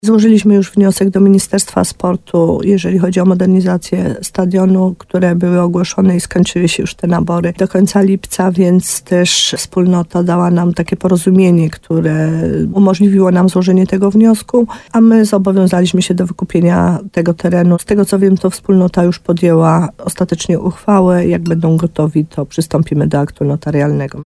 Stanie się to najpóźniej we wrześniu – wyjaśnia wójt gminy Podegrodzie.